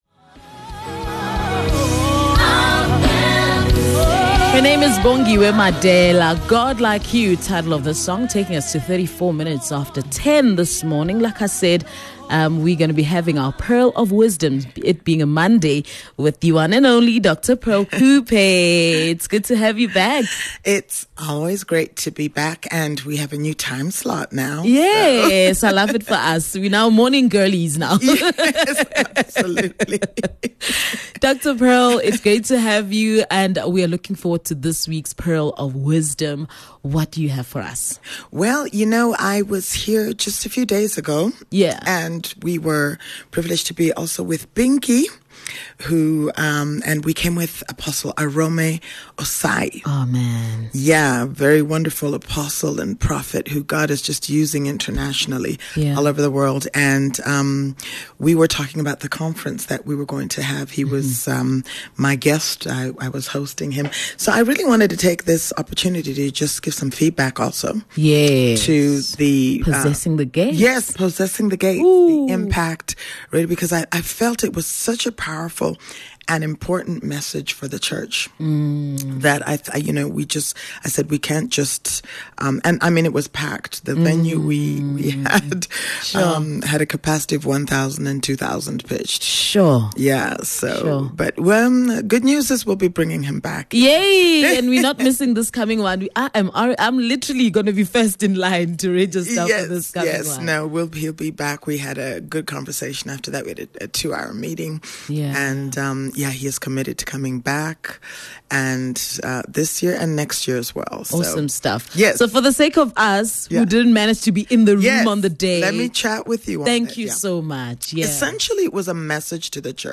18 Sep Pearls of Wisdom - Interview - 18 September 2023